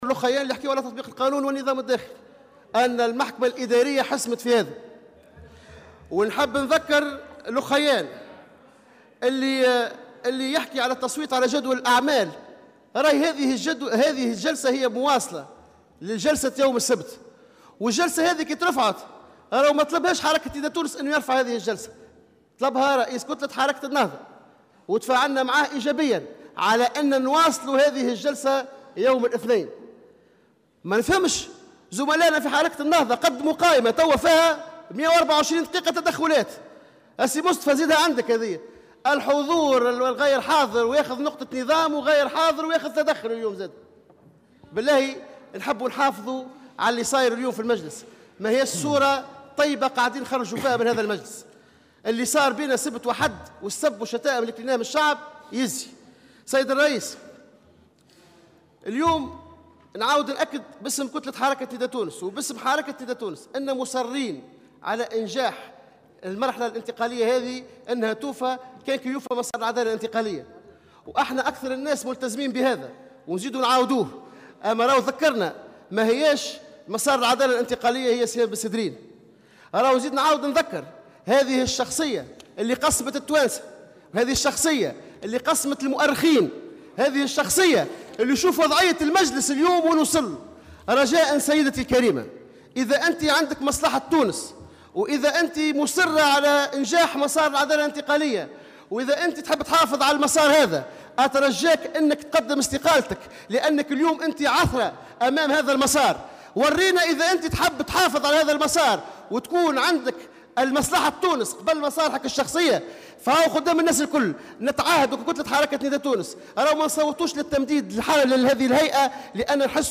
دعا رئيس كتلة نداء تونس سفيان طوبال، اليوم الاثنين، رئيسة هيئة الحقيقة والكرامة سهام بن سدرين الى الاستقالة مؤكدا قرار كتلته بعدم المصادقة على تمديد عمل الهيئة، بحسب تعبيره في مداخلة على هامش جلسة عامة للنظر في تمديد عمل هيئة الحقيقة والكرامة.